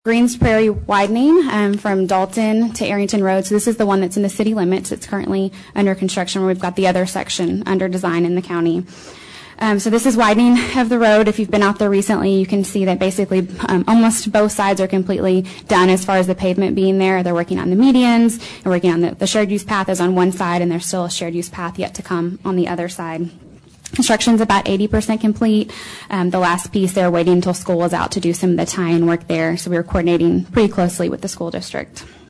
Image from the June 12, 2023 College Station city council meeting.